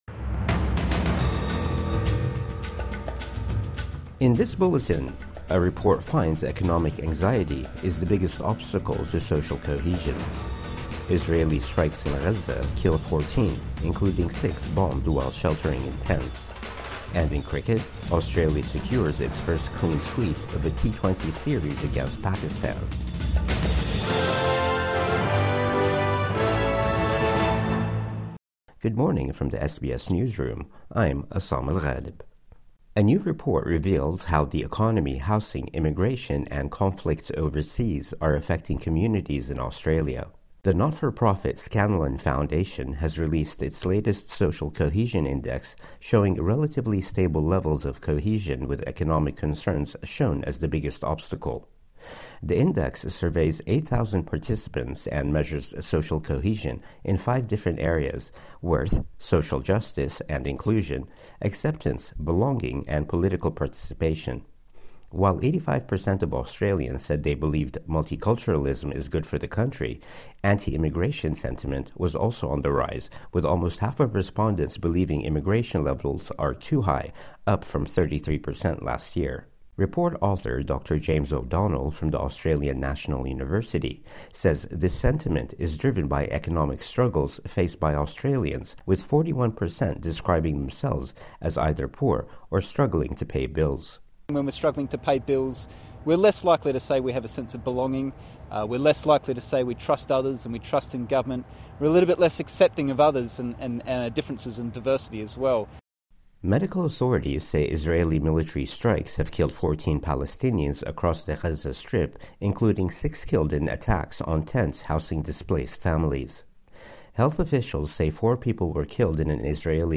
Morning News Bulletin 19 November 2024